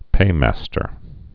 (pāmăstər)